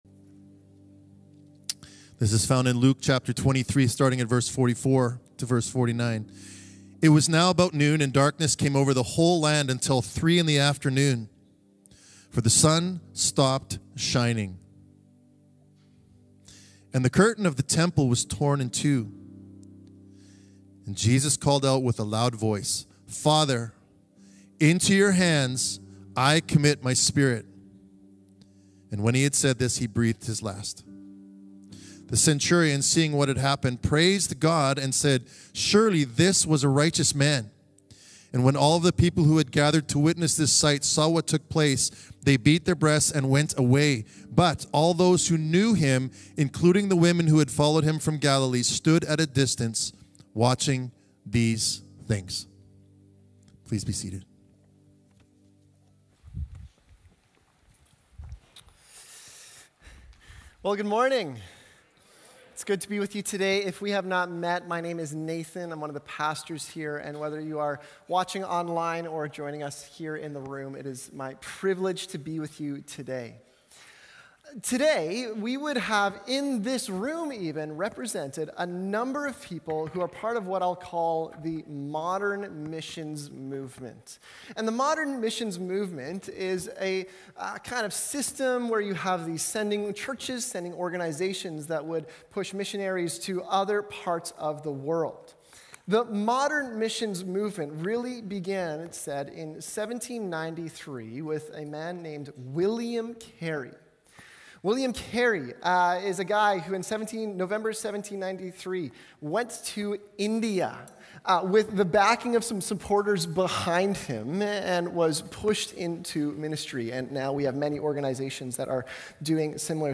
Central Heights Sunday Messages